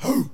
Hhrruuuuu!!!.wav